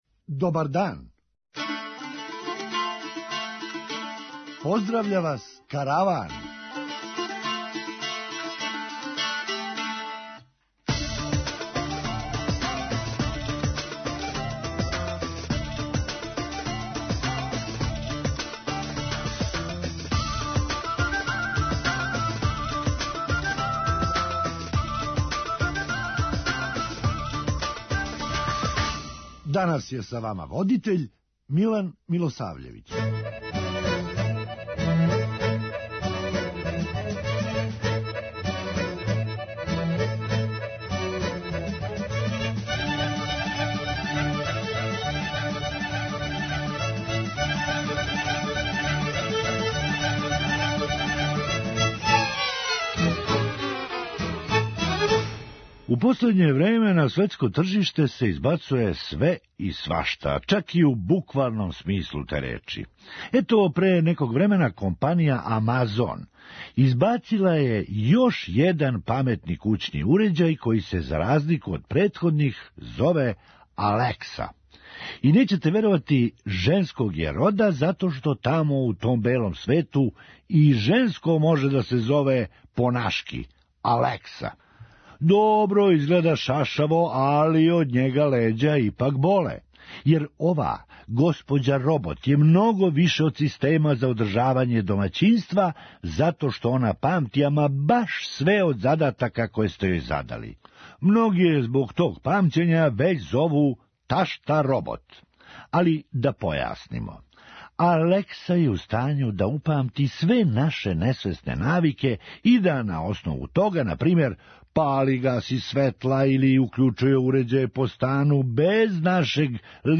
Хумористичка емисија
Остало је нејасно по којој тачки дневног реда је била обављена ова крађа. преузми : 9.82 MB Караван Autor: Забавна редакција Радио Бeограда 1 Караван се креће ка својој дестинацији већ више од 50 година, увек добро натоварен актуелним хумором и изворним народним песмама.